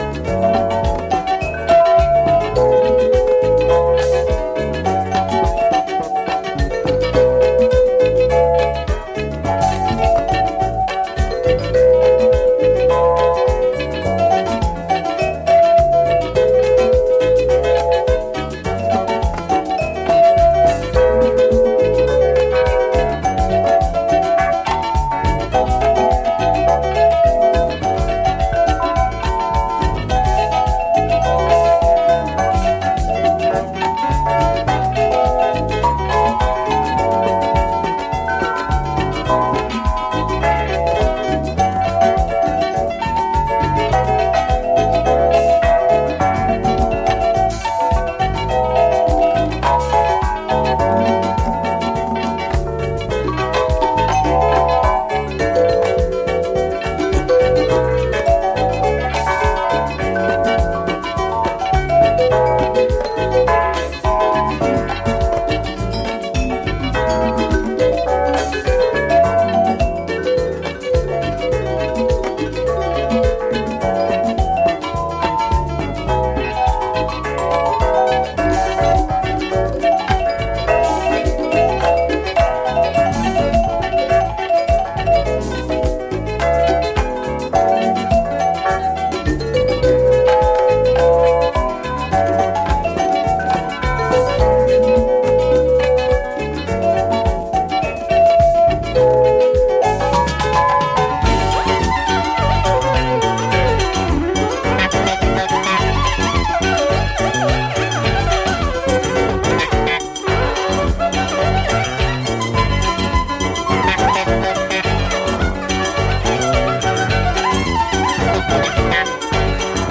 raw Afro-boogie track with a male vocal
Disco Soul Outernational